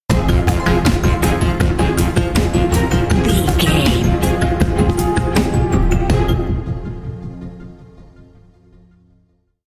Survival horror
Aeolian/Minor
synthesiser